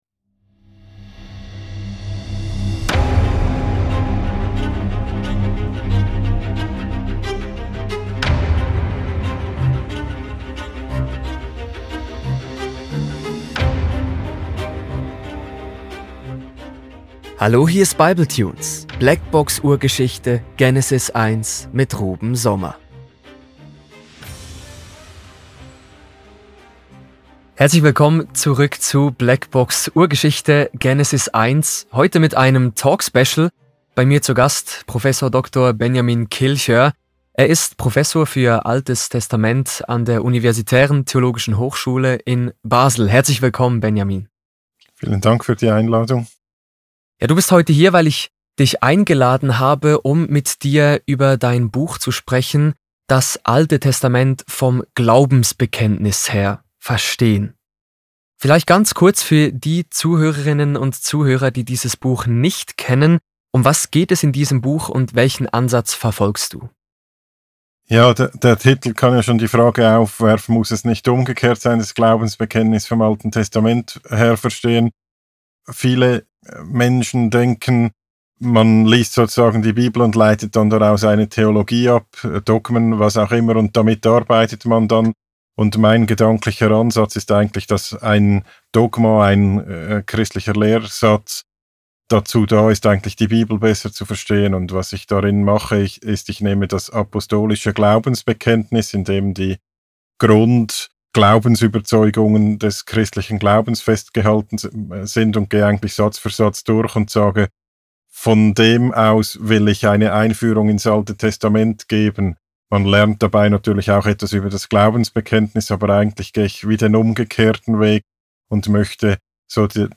Blackbox Urgeschichte: Genesis 1 | Talk-Special